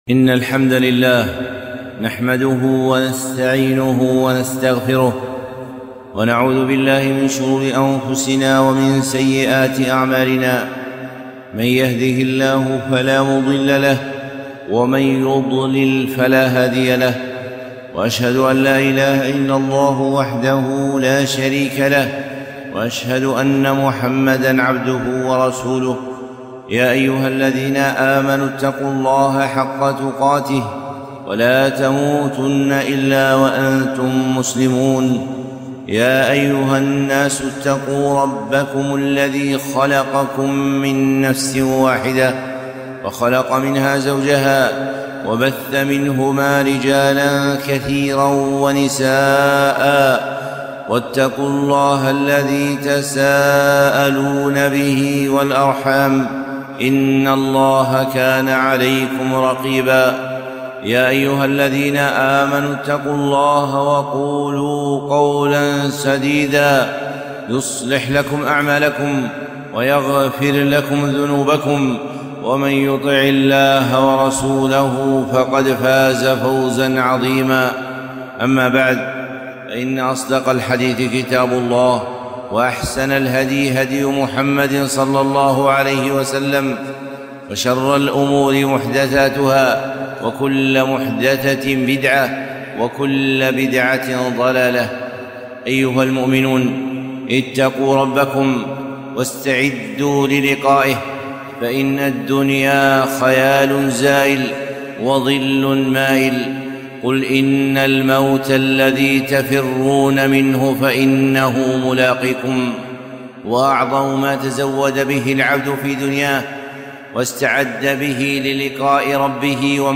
خطبة - عمل قليل وأجر جليل